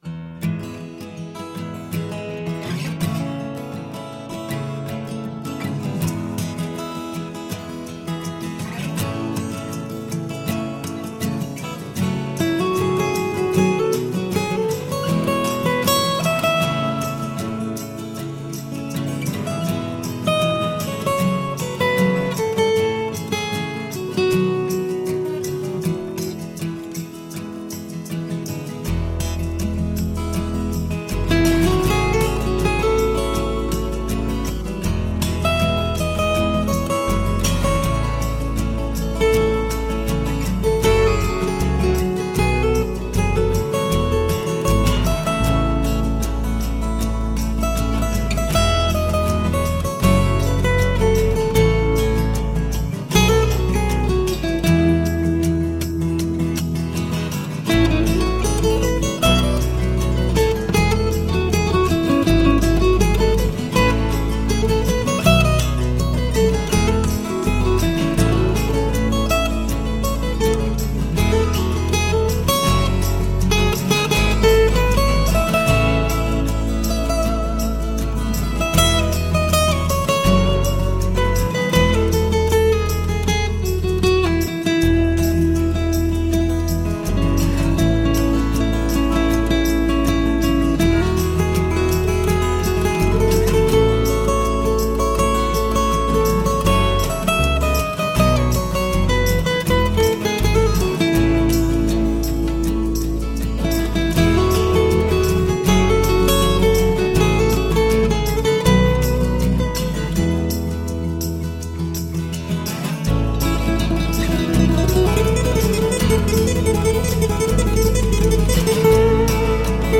Relaxing acoustic guitar duets.
lead acoustic guitar